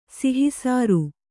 ♪ sihi sāru